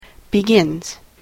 /bɪˈgɪnz(米国英語)/